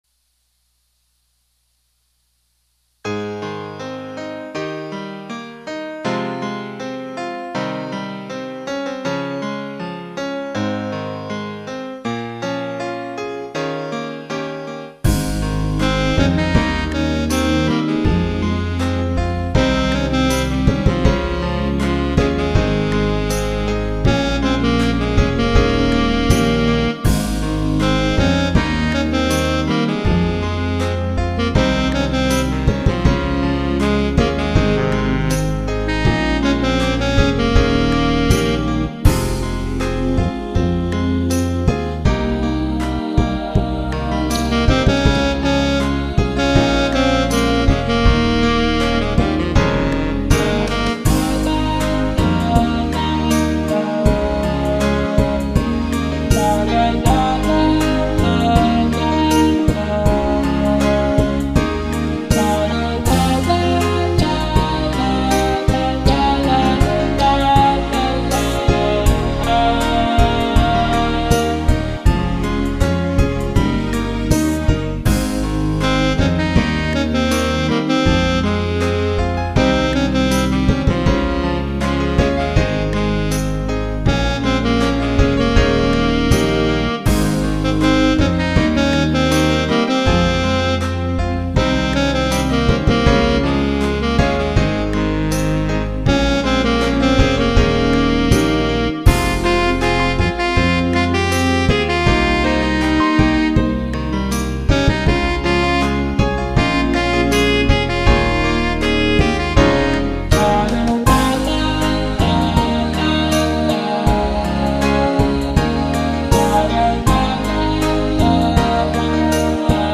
Esta musica � interessante, produzi para testar a performance de meus equipamentos :)
Criei o baixo,o Coral de fundo, e um fraseado com o uso de minhas voz, claro com recursos dos meus equipamentos
Foram sequenciados as trilhas em 7 canais, sendo 1 bateria, 1 strings, 1 pianos, 1 sax, 1 baixo-voz, 1 Coral-voz, 1 fraseado-voz O equipamento essencial foi um MIDI-Vocalist da Digitech MV-5.